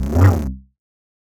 alert05.ogg